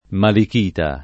malichita [ malik & ta ] → malechita